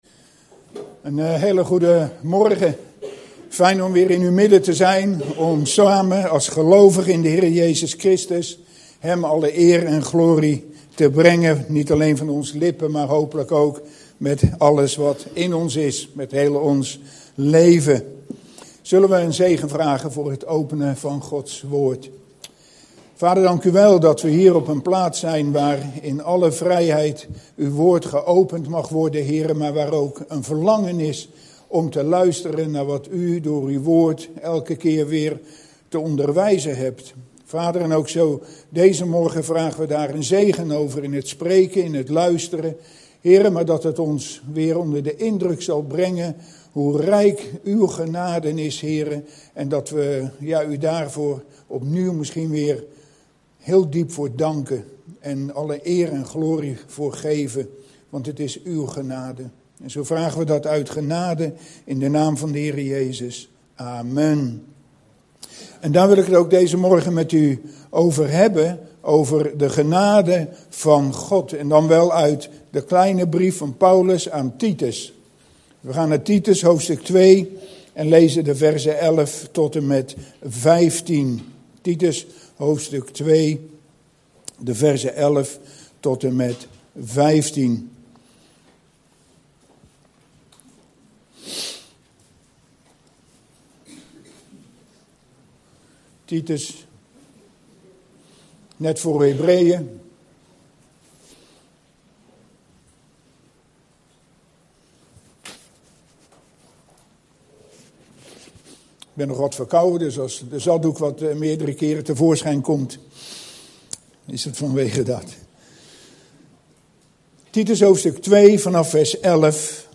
In de preek aangehaalde bijbelteksten (Statenvertaling)